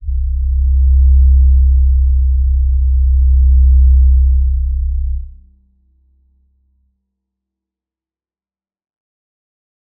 G_Crystal-C2-mf.wav